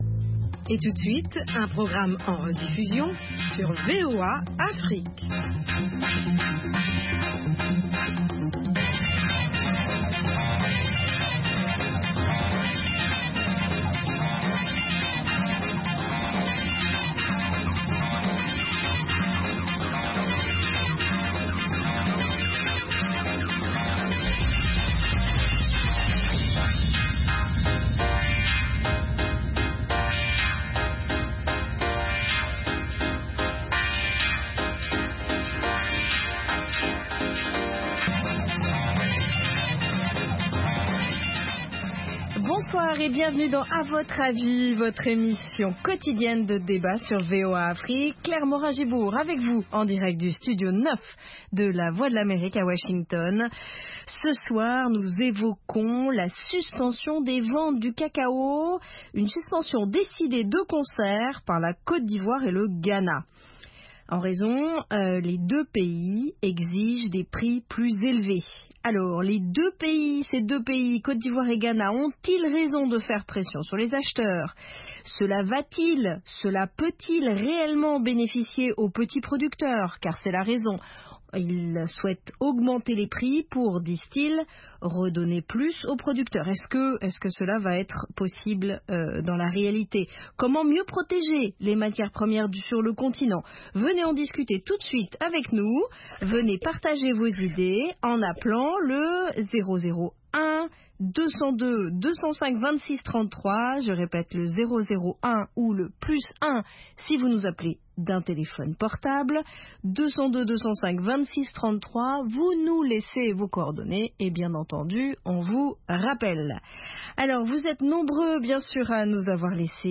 Le programme quotidien d'appel de VOA Afrique offre aux auditeurs un forum pour commenter et discuter d'un sujet donné, qu'il s'agisse d'actualités ou de grands sujets de débat.